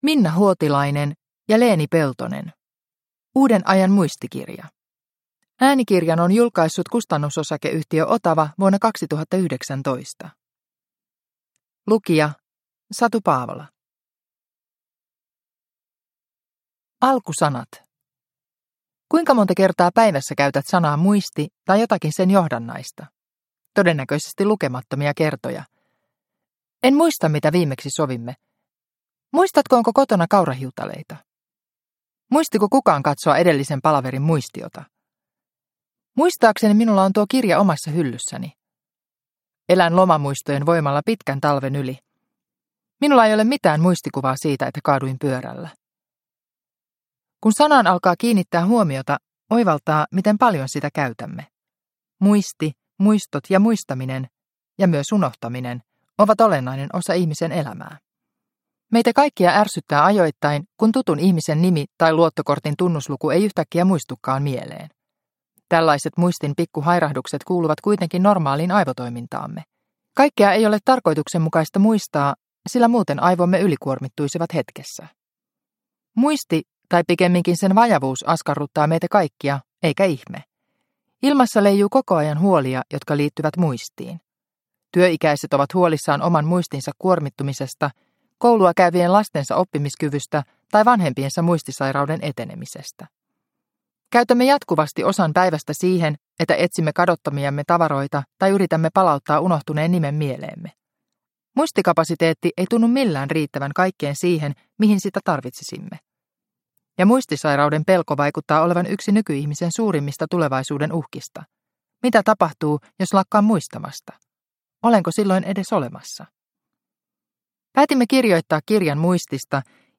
Uuden ajan muistikirja – Ljudbok – Laddas ner